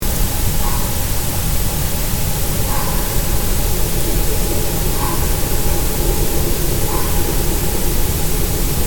Audioaufnahmen aus dem Schutzgebiet
kolkrabe.mp3